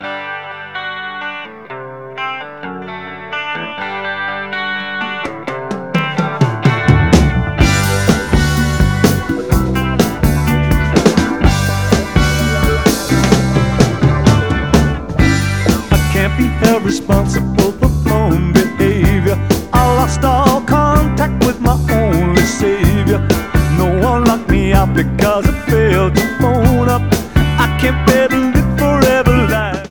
Sound Samples (All Tracks In Stereo Except Where Noted)
Mono